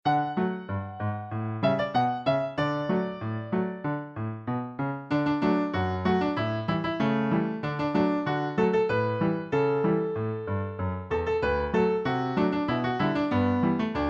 Children's Song Lyrics and Sound Clip
Folk Song Lyrics